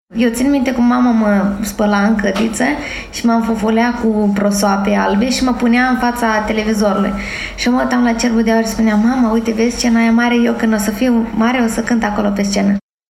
Cu acestă ocazie, artista a acordat un interviu în exclusivitate pentru Radio Romania Brașov, în care a povestit despre cum a primit invitația de a participa la Cerbul de Aur și ce amintiri are despre celebrul festival.